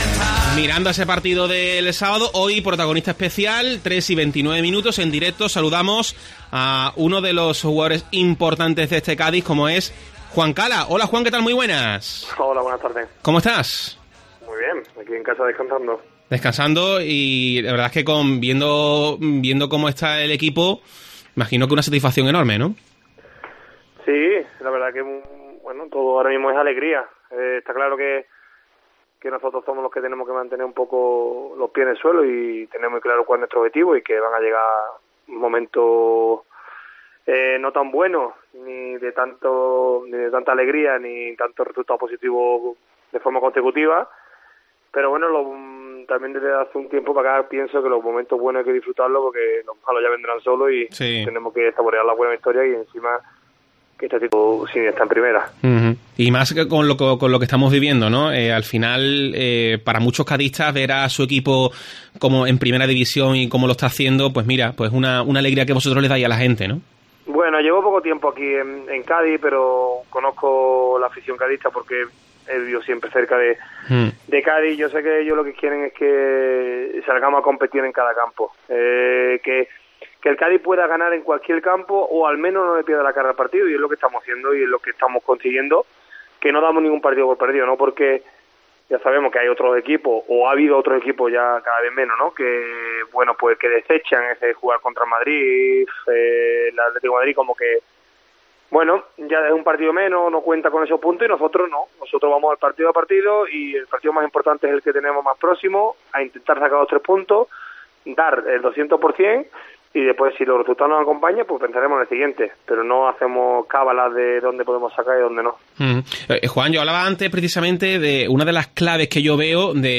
Juan Cala analiza el gran comienzo del Cádiz CF en Deportes COPE Cádiz
El jugador analiza el comienzo liguero en los micrófonos de COPE Cádiz.